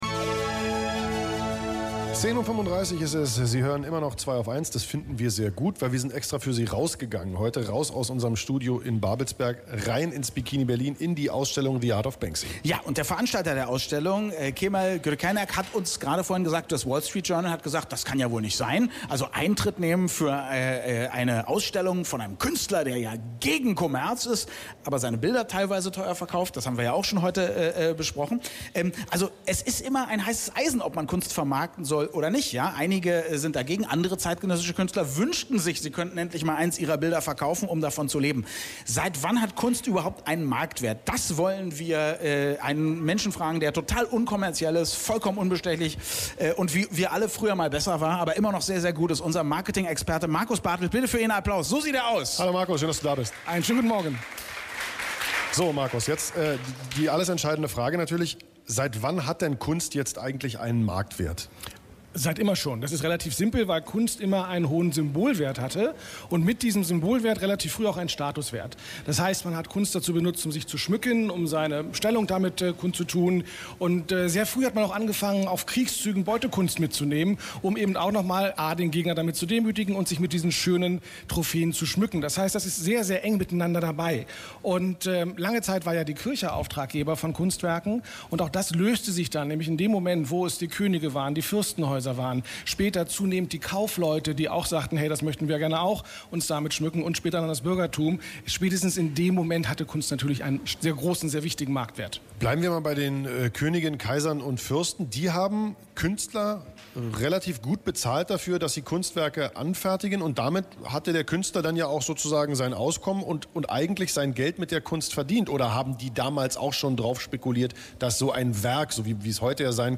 Kunst und Marketing, Marketing und Kunst, diese zwei Begriffe gehören untrennbar zueinander. Und da die Herren von „Zweiaufeins“ live von der Ausstellung „The Art of Bansky“ senden, wurde ich eingeladen, diesen Zusammenhang einmal kurz zu beleuchten: